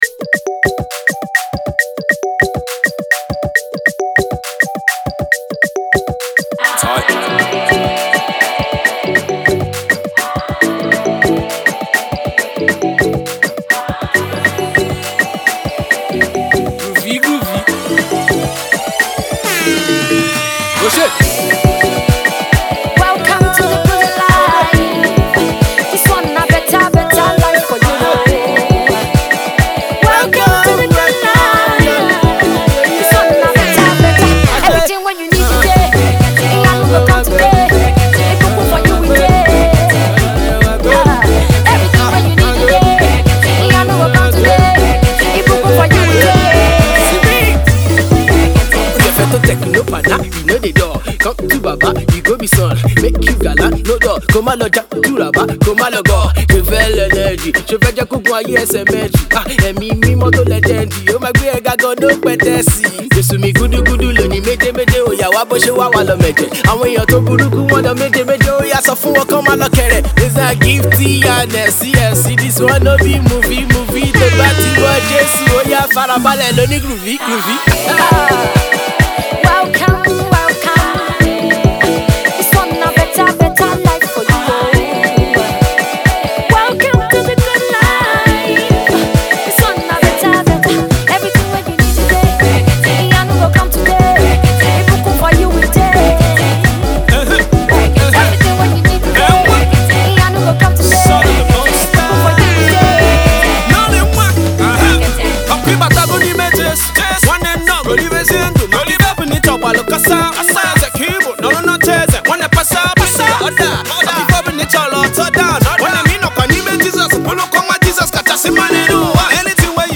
It features two indigenous rappers